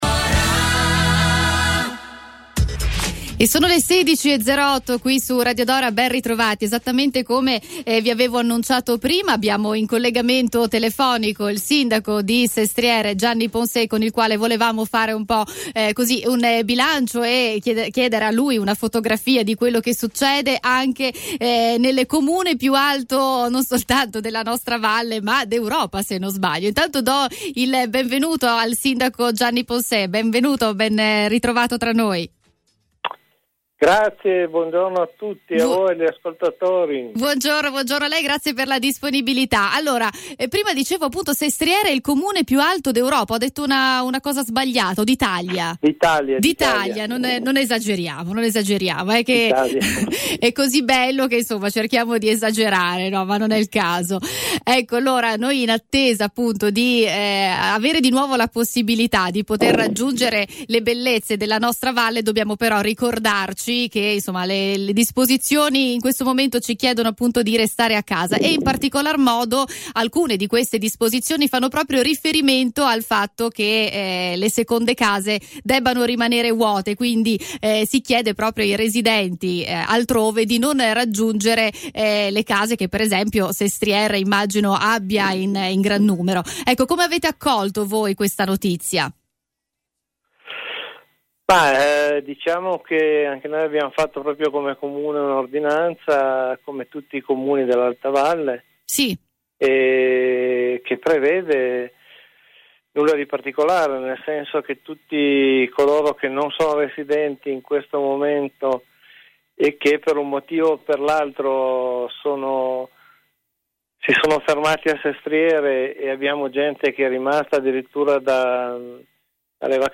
Per riascoltare l’intervista col sindaco Poncet, potete cliccare sul link qui in basso.